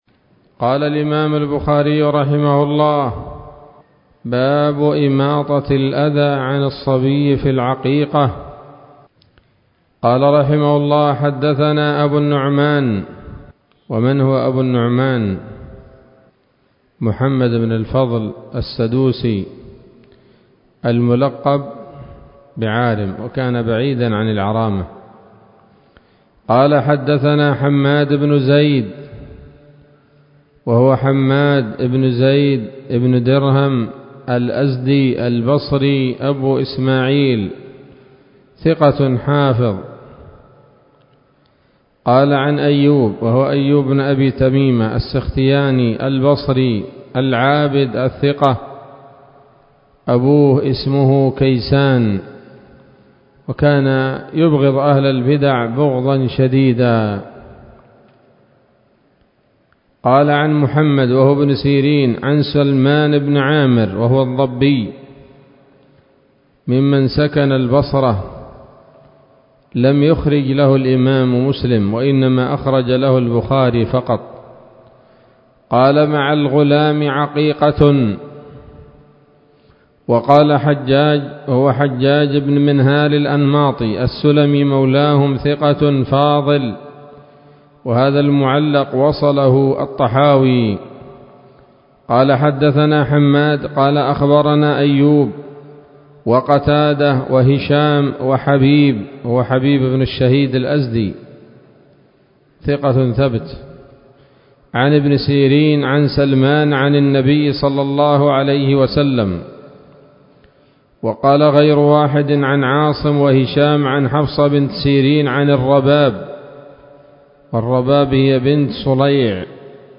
الدرس الثاني من كتاب العقيقة من صحيح الإمام البخاري